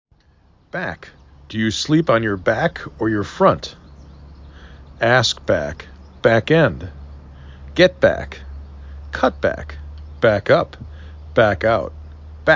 a = had, ant